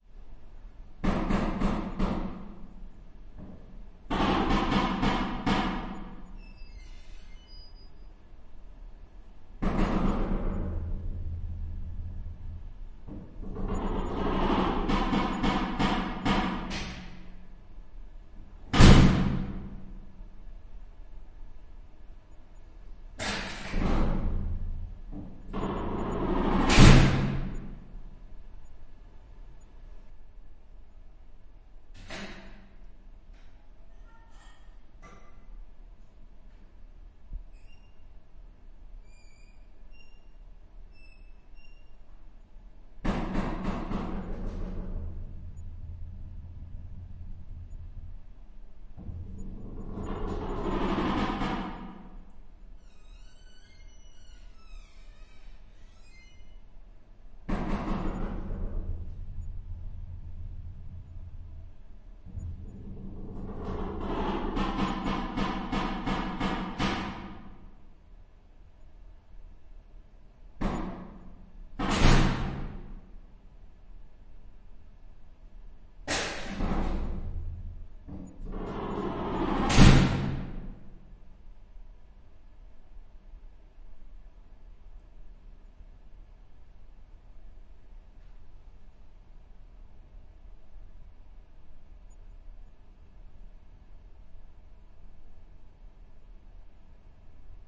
描述：一扇钢门，在一个静音的混凝土办公楼楼梯下8米。
标签： 命中 吱吱 踩住 混凝土 金属 金属 防火 办公室 走廊 冲击
声道立体声